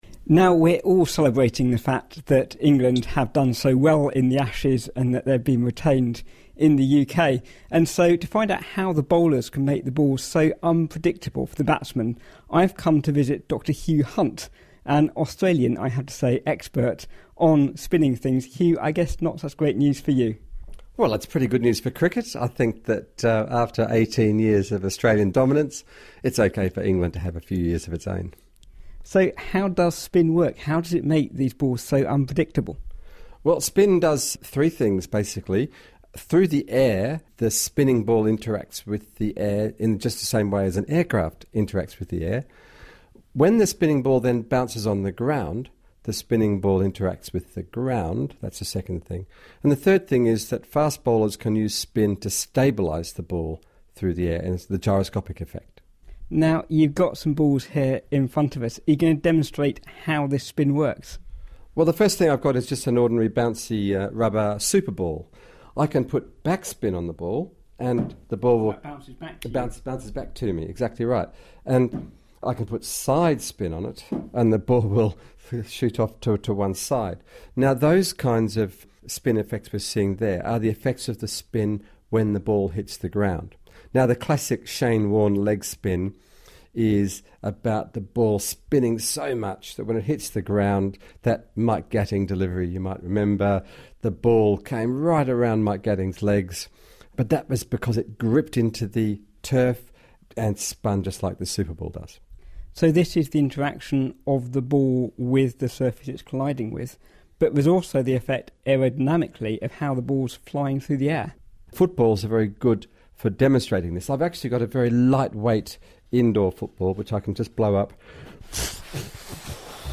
to bounce some balls around in his office at Trinity College Cambridge